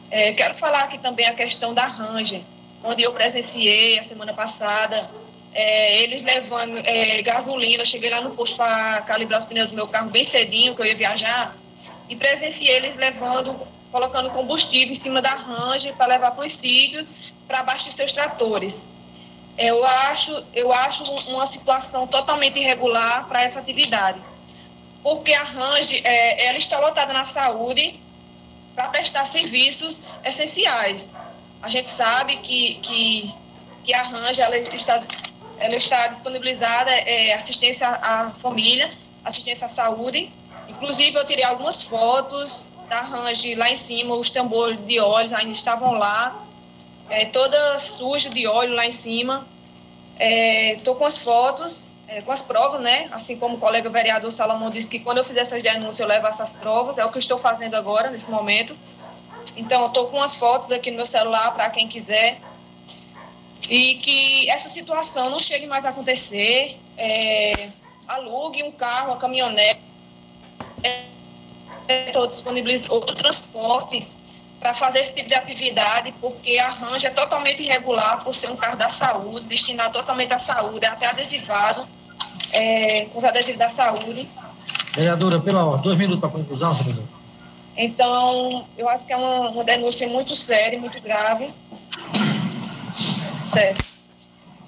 Ocorreu na tarde / noite dessa sexta-feira (12), a sessão ordinária da Câmara de Vereadores do município de Santa Terezinha (PB).
Devido à pandemia da Covid-19, a reunião foi realizada de maneira remota com transmissão da Rádio Conexão (104,9 FM).